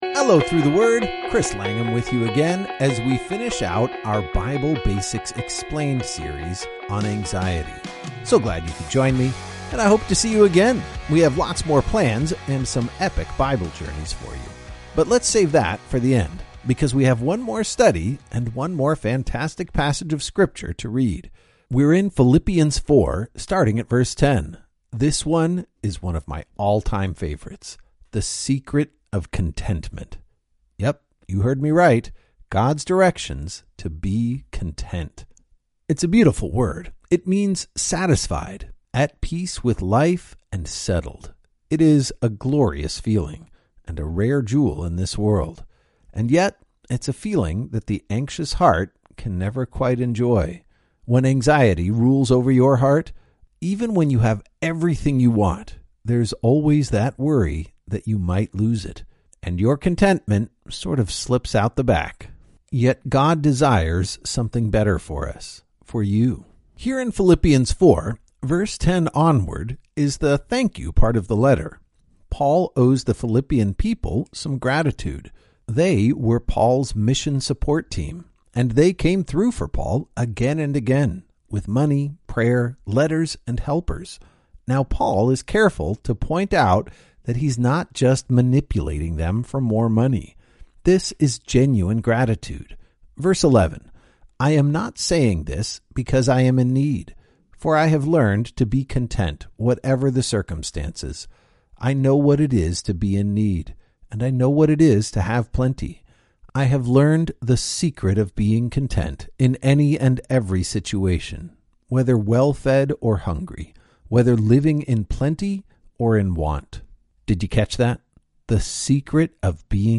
This devotional works best as an audio experience.